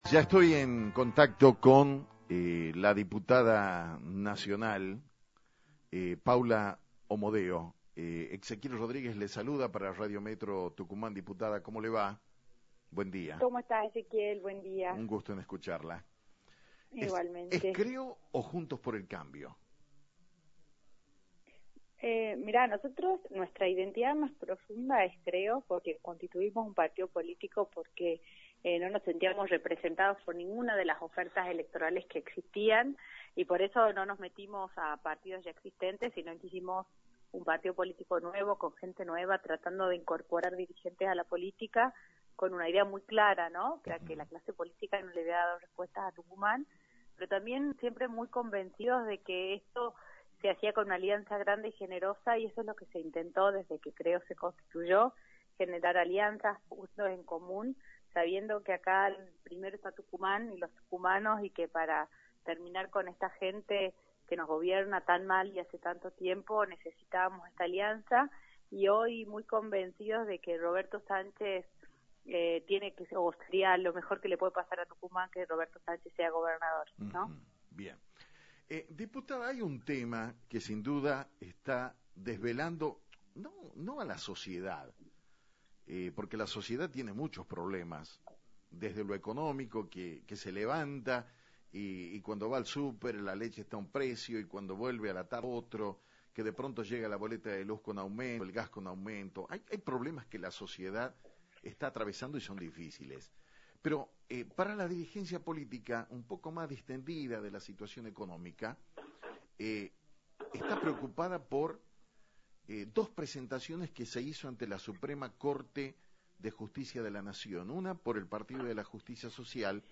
la diputada nacional de CREO, Paula Omodeo, habló sobre el pedido que dicho partido hizo a la CSJN